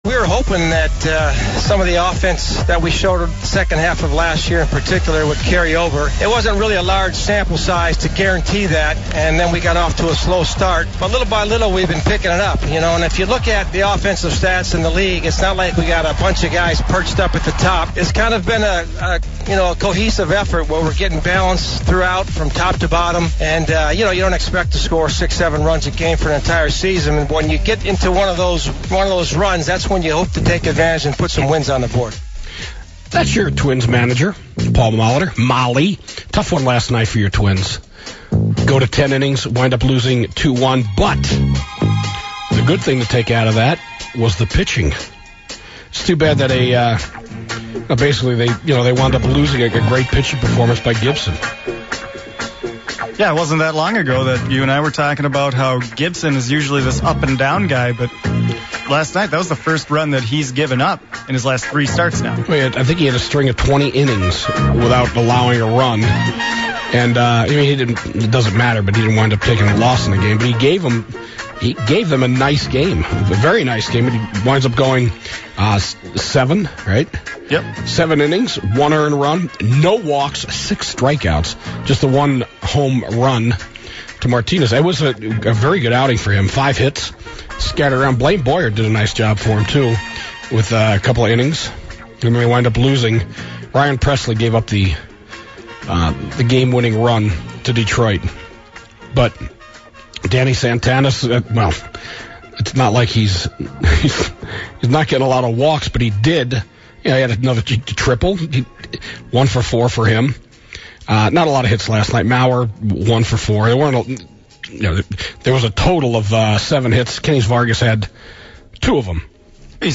comes in studio to talk about his organization and the great things he has going on.